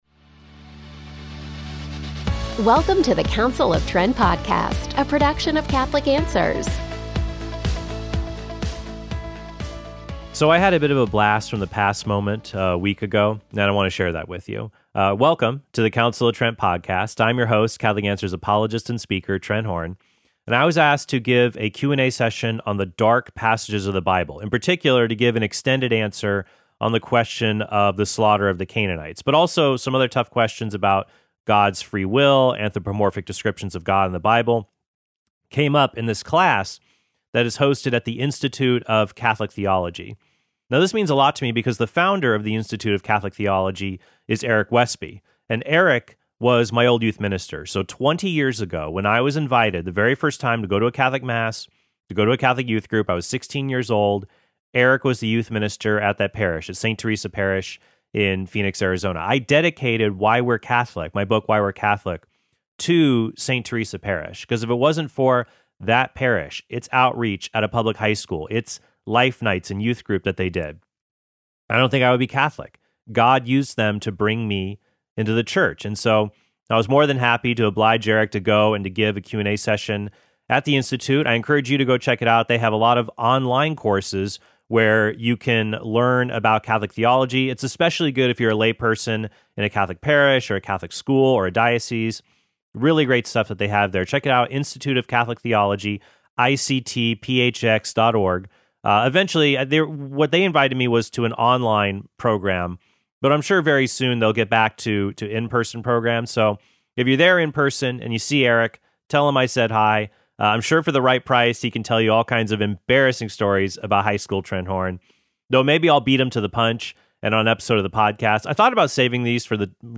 guest appearance at the Institute of Catholic Theology